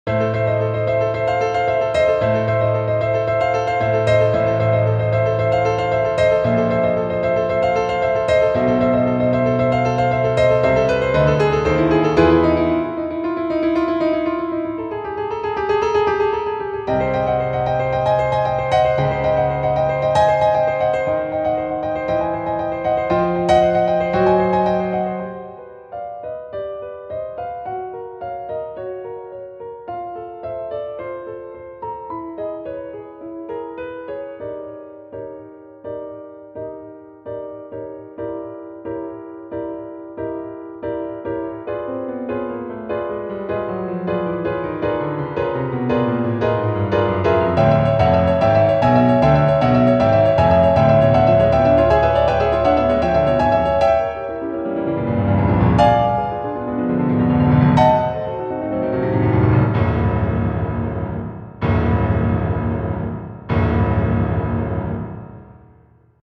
ogg(R) 嫉妬 激しい ピアノ
想いをぶちまけるピアノ独奏。